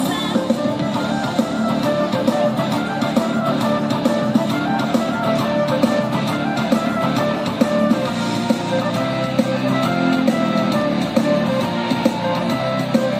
Mám podobný dotaz, co je tohle za lidovou taneční písničku tradiční pro Slovensko?